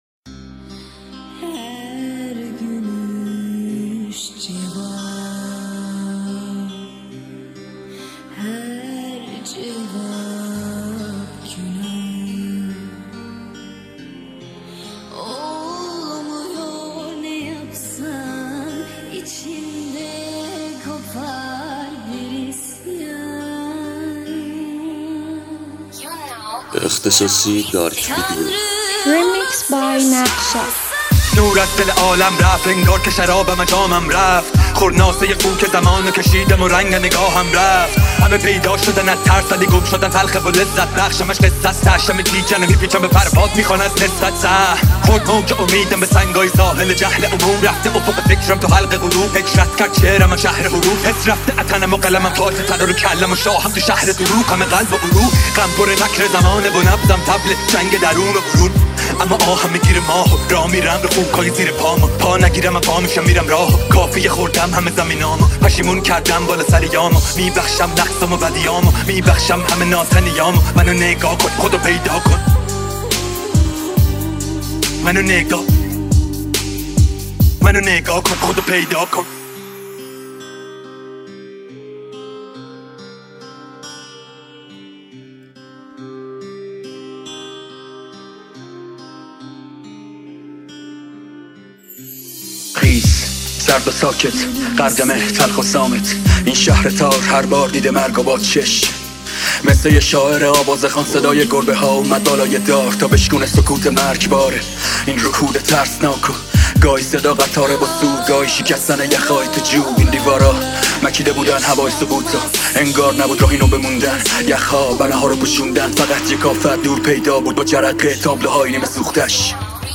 دانلود ریمیکس جدید رپ